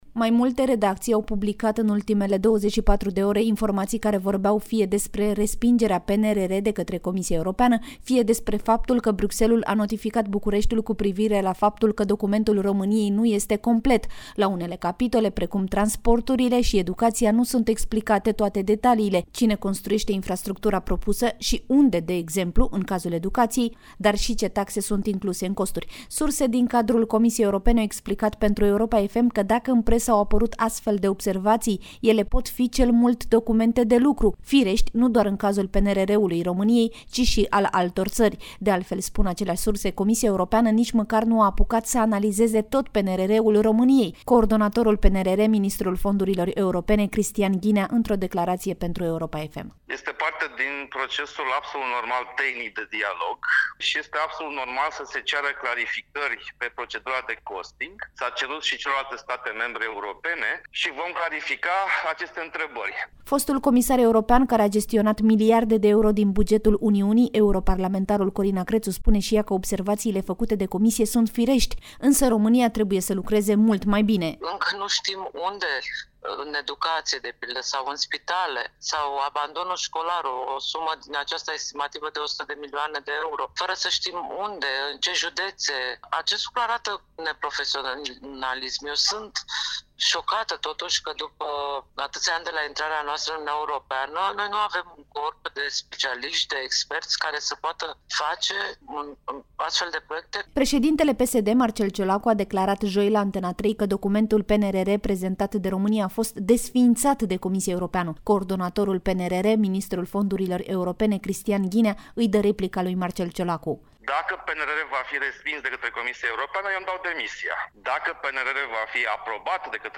Coordonatorul PNRR, ministrul Fondurilor Europene, Cristian Ghinea, într-o declarație pentru Europa FM: